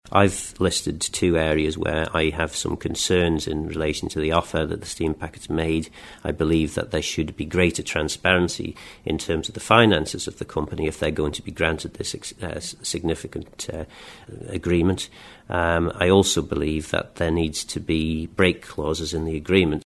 The Steam Packet Company has already put forward a proposal to continue running ferry services however Minister Phil Gawne says he's not entirely happy with it: